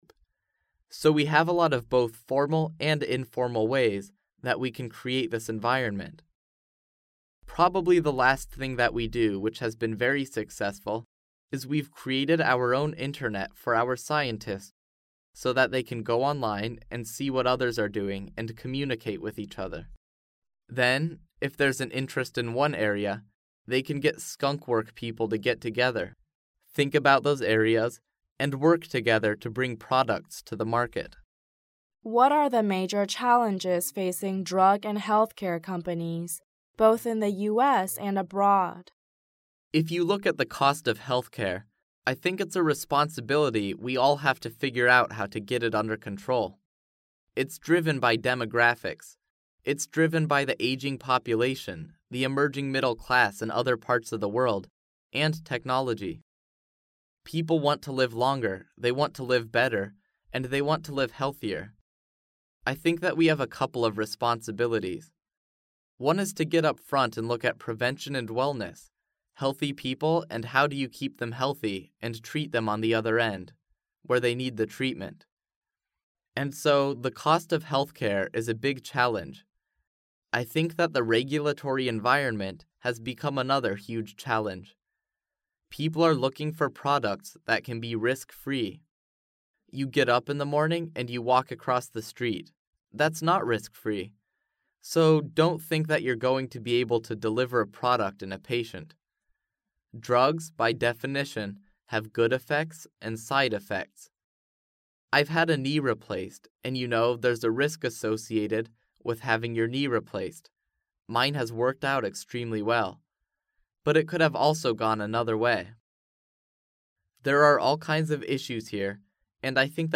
世界500强CEO访谈